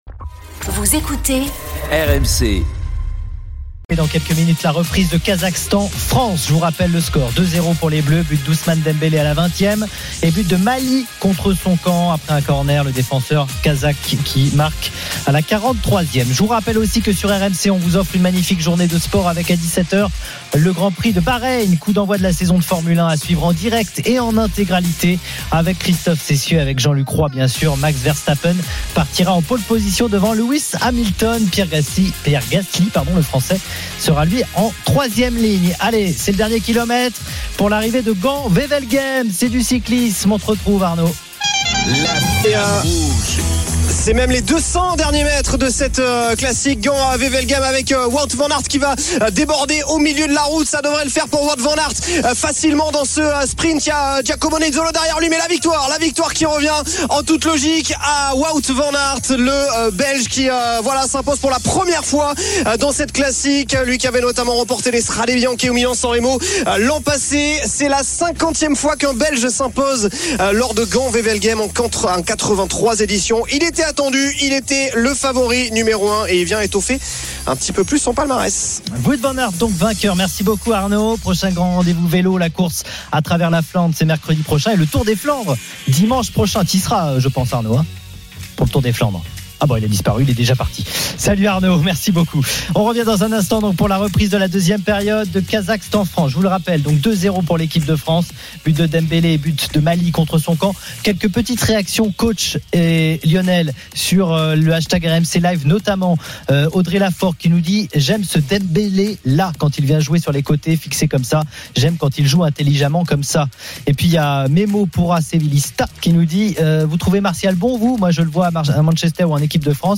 Tous les matchs en intégralité, sur RMC la radio du Sport.